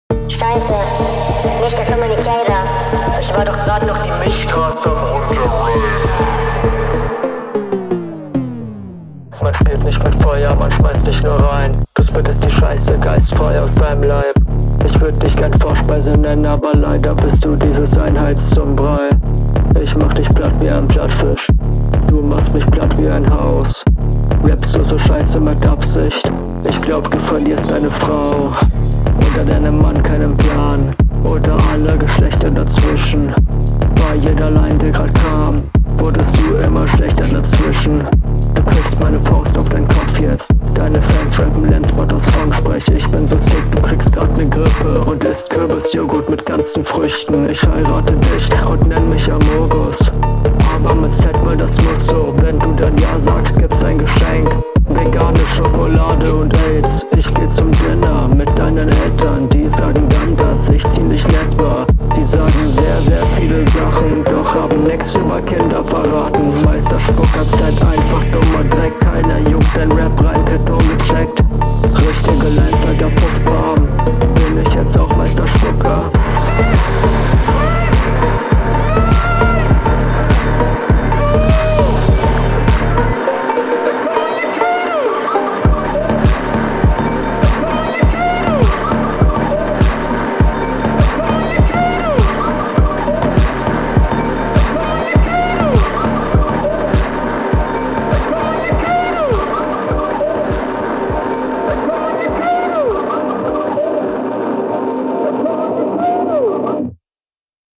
Ich find' die Idee mit dem Effekt in Verbindung mit dem Beat sehr nice.